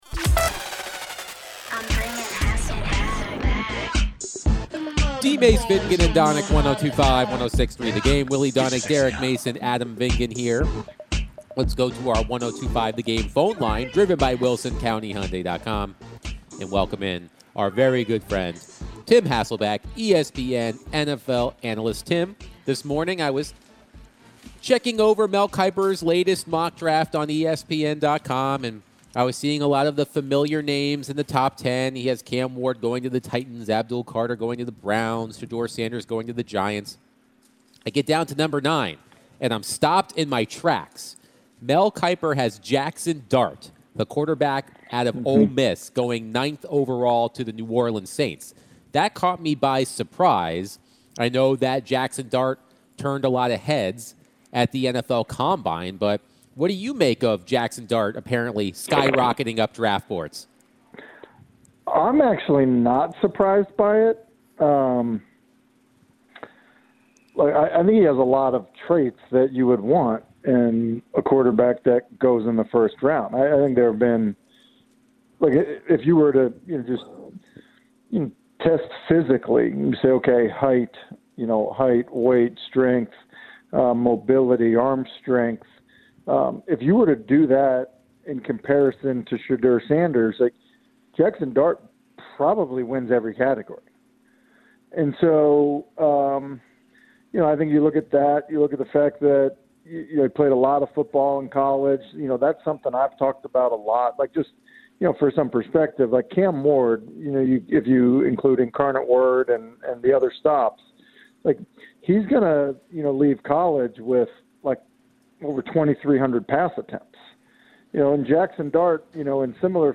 ESPN NFL analyst Tim Hasselbeck joined the show and shared his thoughts about the top prospects in the upcoming NFL Draft. Tim was specifically asked about Sheduer Sanders and Cam Ward.